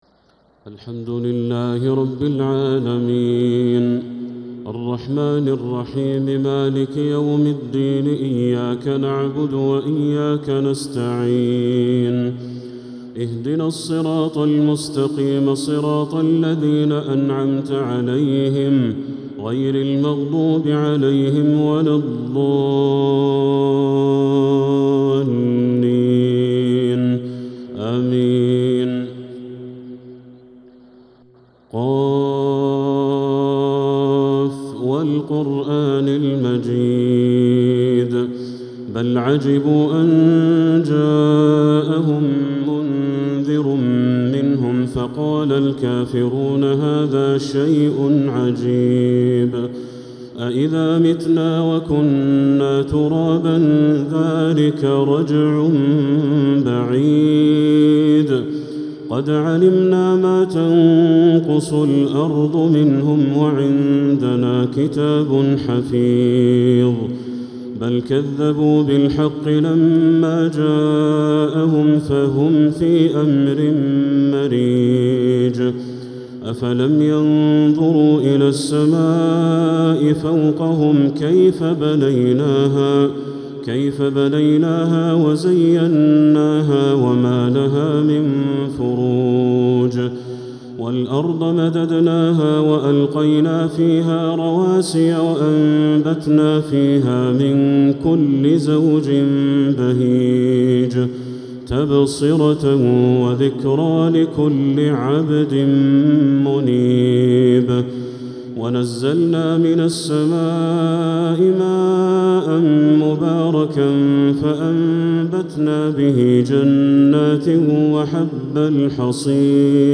تراويح ليلة 27 رمضان 1446هـ من سورة ق الى سورة النجم (1-32) | Taraweeh 27th night Ramadan 1446H Surah Qaf to An-Najm > تراويح الحرم المكي عام 1446 🕋 > التراويح - تلاوات الحرمين